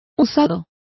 Complete with pronunciation of the translation of worn.